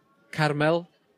ynganiad ).